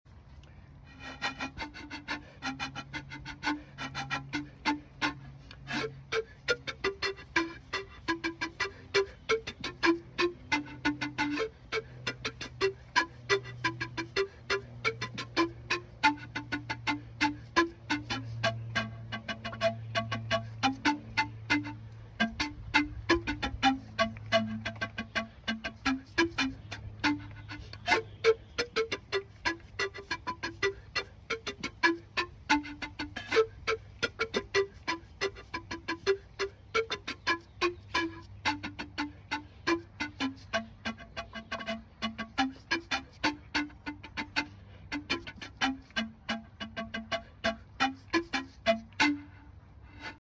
Zampoña The Andean Pan sound effects free download
The Andean Pan Mp3 Sound Effect Zampoña - The Andean Pan flute - Natural Sound . (No effects)